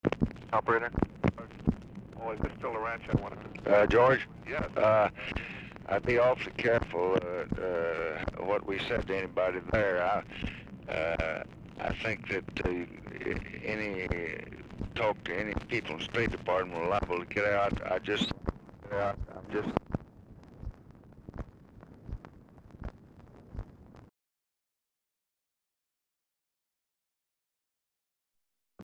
"RE CYPRUS SITUATION"; BALL SPEAKS TO OPERATOR BEFORE LBJ TAKES CALL
Format Dictation belt
Location Of Speaker 1 LBJ Ranch, near Stonewall, Texas
Specific Item Type Telephone conversation